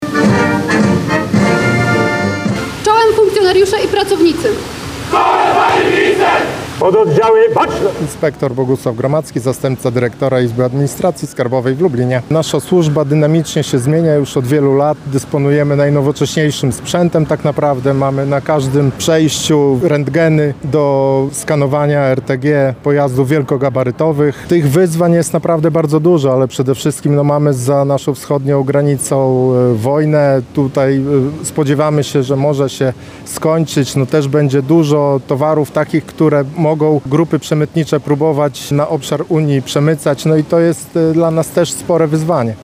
W Lublinie zakończyły się obchody Dnia Administracji Skarbowej. Była to okazja między innymi do podziękowań, a także wręczenia odznaczeń służbowych.